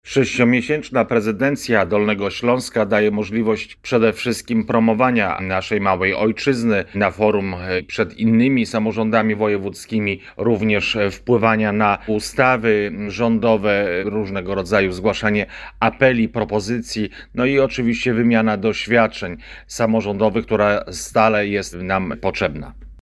Co to oznacza w praktyce – tłumaczy Andrzej Kredkowski – Wiceprzewodniczący Sejmiku Województwa Dolnośląskiego.